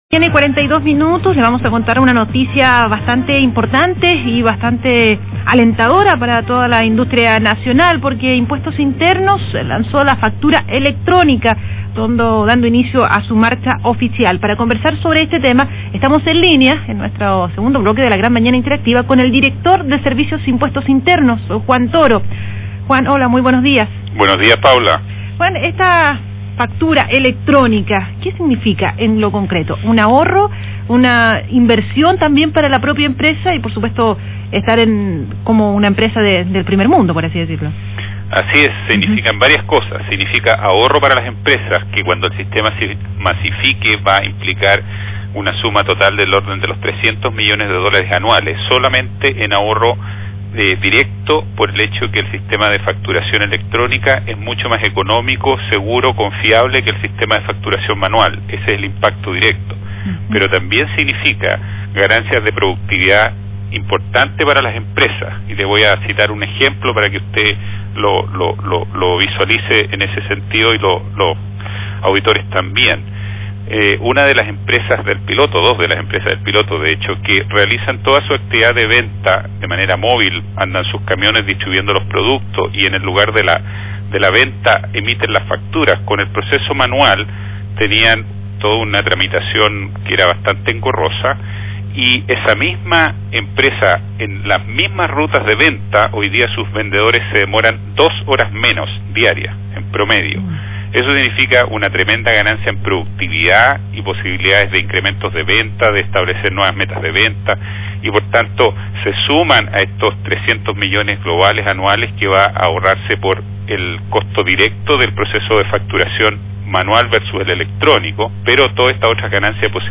Medios Radiales
Entrevista del Director del SII, Juan Toro, sobre factura Electrónica (Agricultura 4/9/2003)